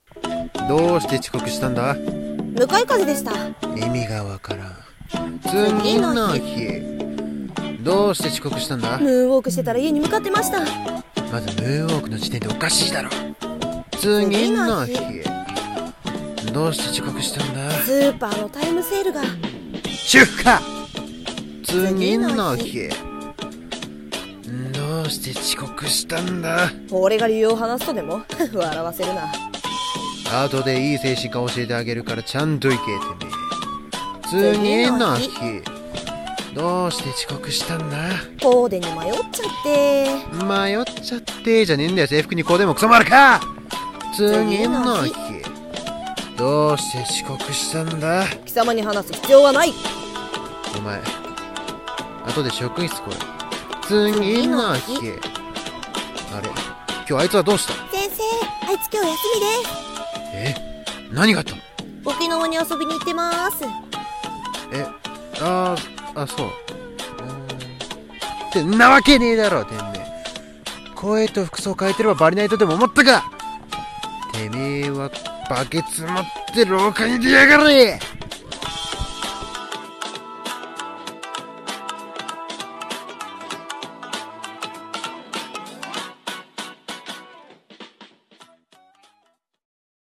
声劇「遅刻の理由」